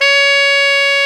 Index of /90_sSampleCDs/Roland L-CD702/VOL-2/SAX_Tenor V-sw/SAX_Tenor _ 2way
SAX TENORM0Q.wav